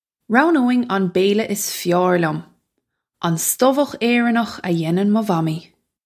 Pronunciation for how to say
Row-noe-in on bayl-yuh iss fear lyum - on sto-wukh Ay-run-nukh uh yay-nun muh wommee
This is an approximate phonetic pronunciation of the phrase.